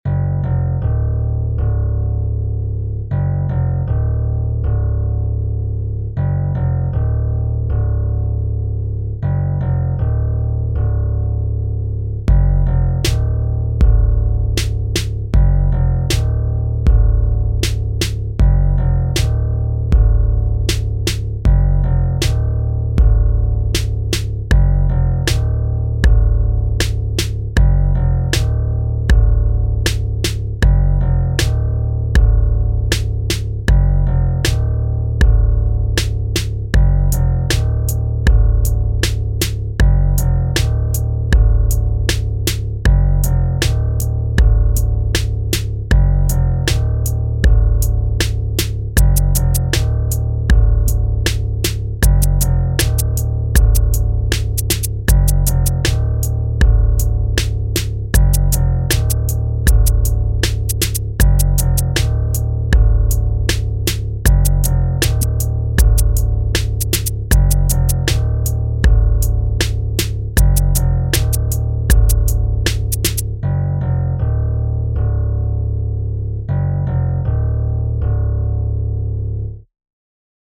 Jo nur der Beat eingereicht, wenn ich das richtig sehe.